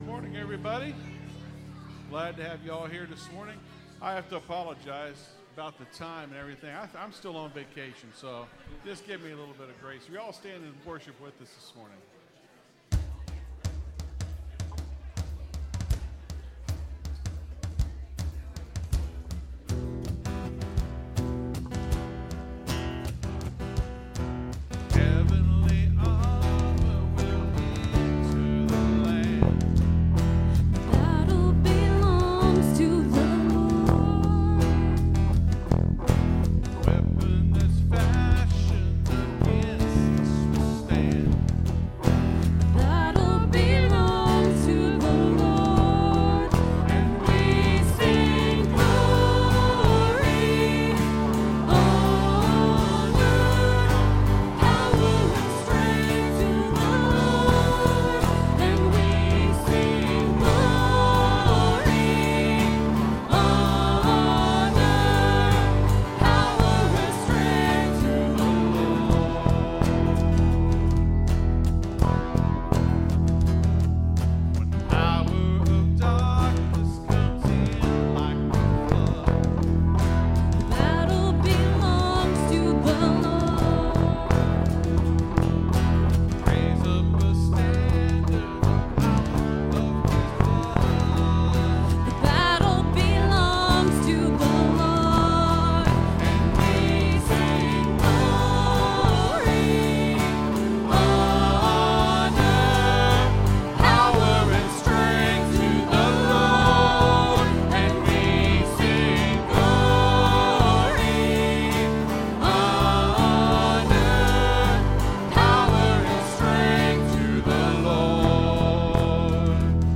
(Sermon starts at 26:20 in the recording).